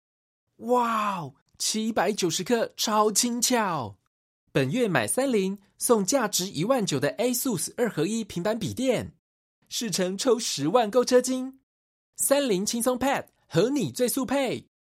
MN KC COM 01 Commercials Male Mandarin